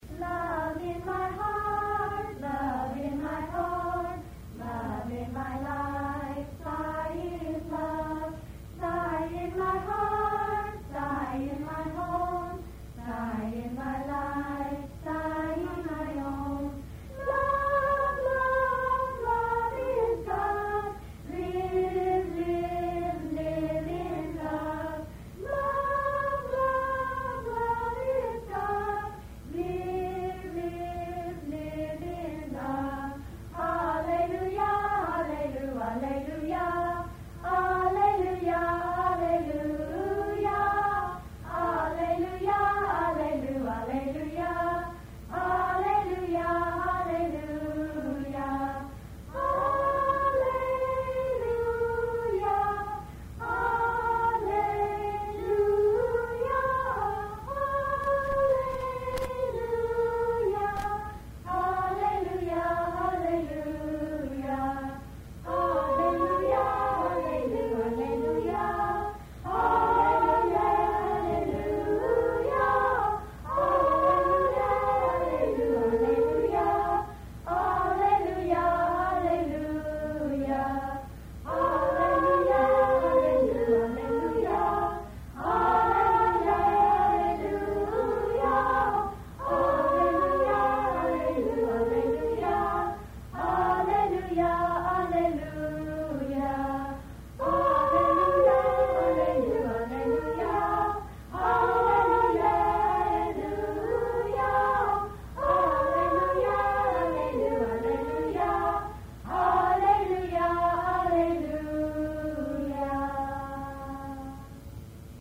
1. Devotional Songs
8 Beat / Keherwa / Adi
Medium Slow
3 Pancham / E
7 Pancham / B
Lowest Note: p / G (lower octave)
Highest Note: S / C (higher octave)